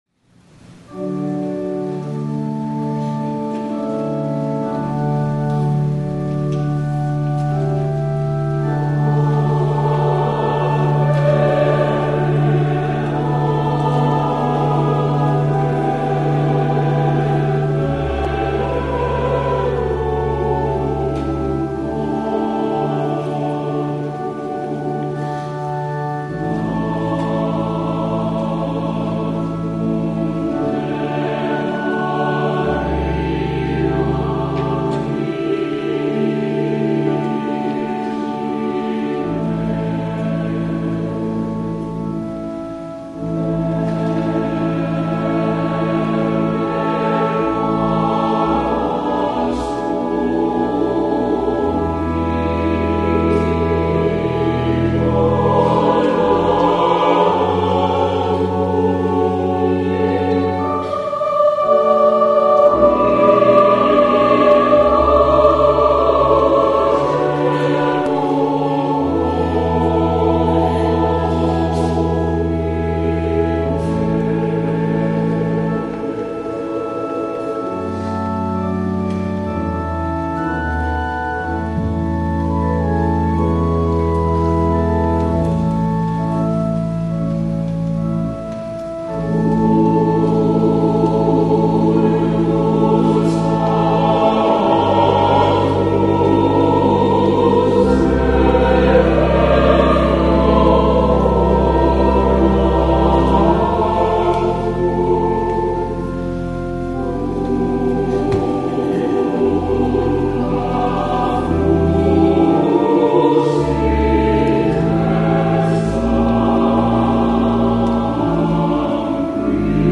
WORLD COMMUNION SUNDAY
THE ANTHEM
Ave Verum Corpus (sung in Latin) W. A. Mozart